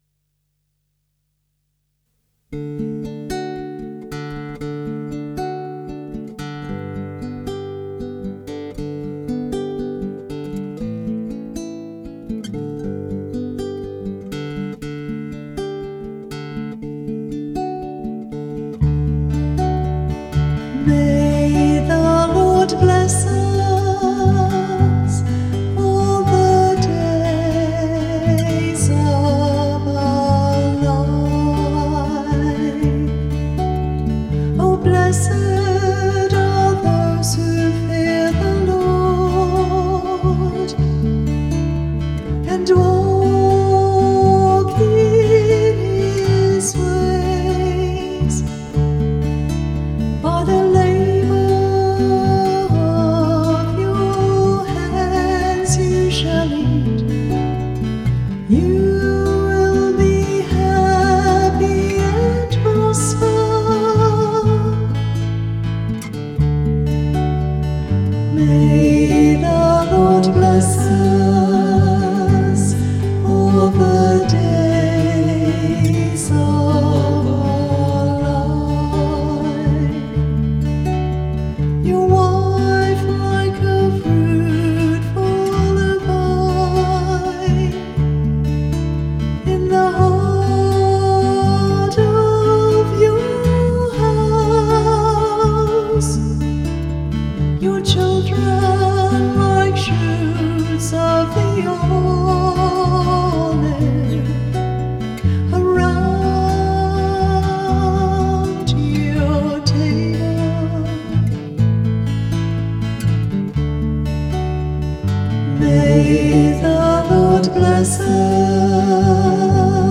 The music for the psalm is an original OLOR composition.
Music by the Choir of Our Lady of the Rosary RC Church, Verdun, St. John, Barbados.